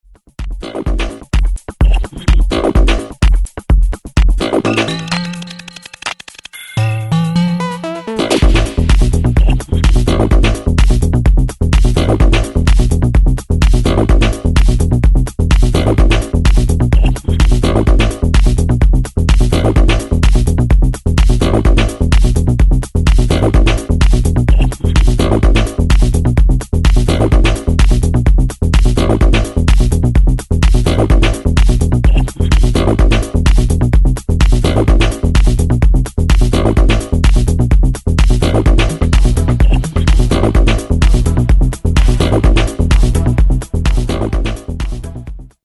Styl: Electro, Techno